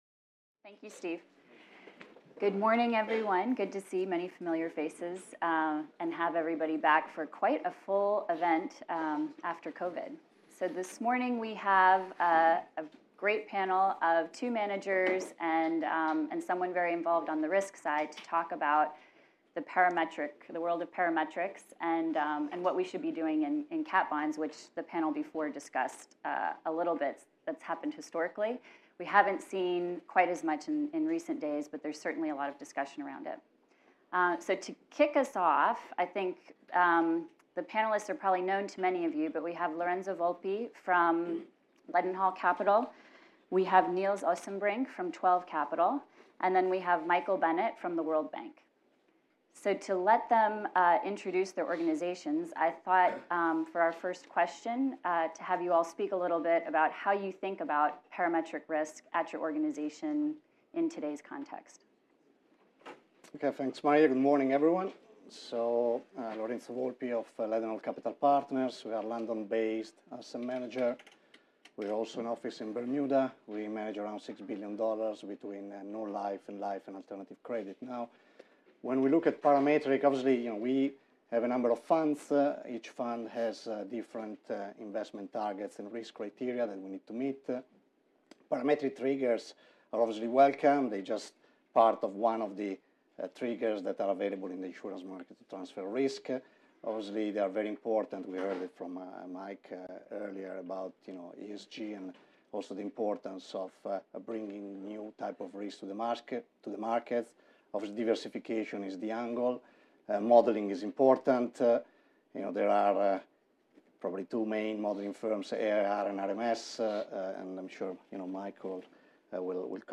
This second video from our Artemis London 2022 conference in September features a panel discussion on climate, disaster risk and parametric catastrophe bonds, under the title of "Filling the Gaps".